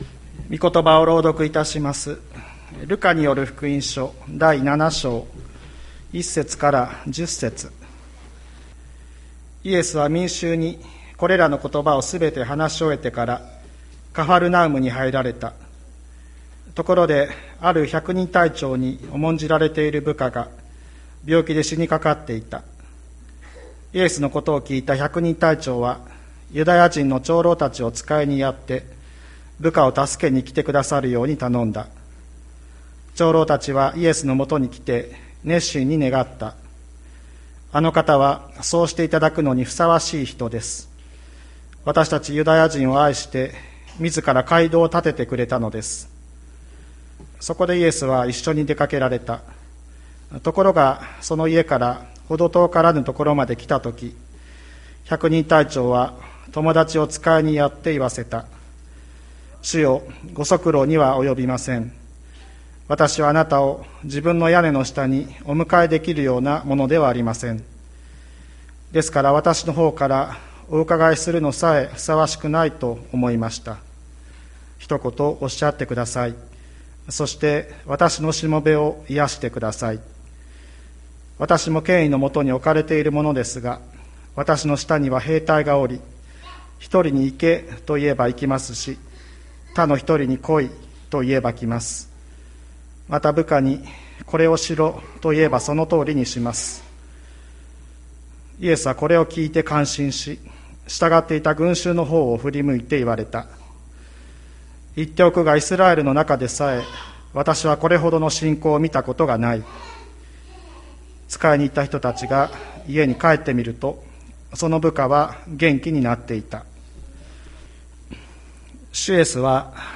2022年07月24日朝の礼拝「ひとことで救われる」吹田市千里山のキリスト教会
千里山教会 2022年07月24日の礼拝メッセージ。